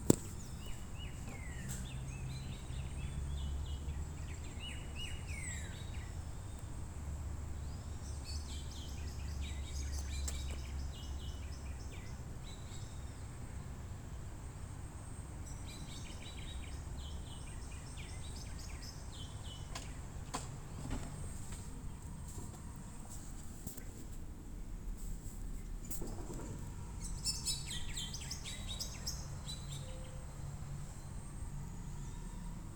Pepitero Gris (Saltator coerulescens)
Nombre en inglés: Bluish-grey Saltator
Localidad o área protegida: San Miguel de Tucumán
Condición: Silvestre
Certeza: Vocalización Grabada
Pepitero-gris23.2.26.mp3